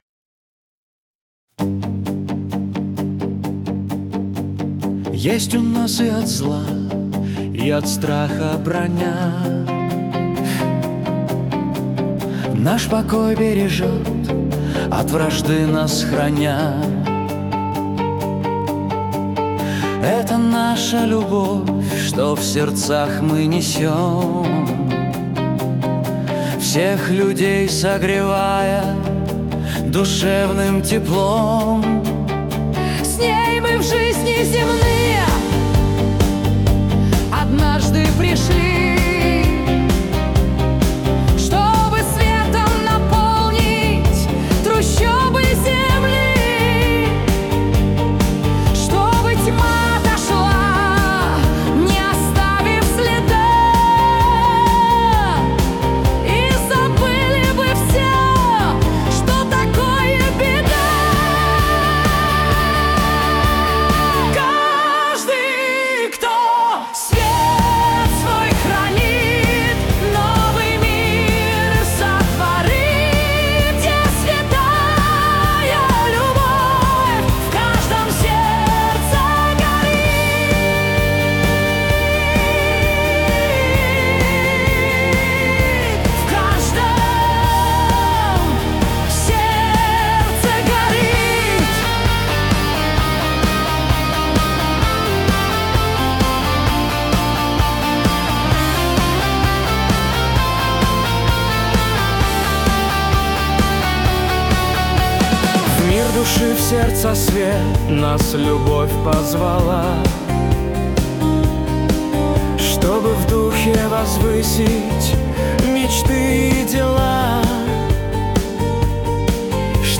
кавер-версия